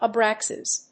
/ʌˈbræksʌz(米国英語)/
フリガナアブラックサズ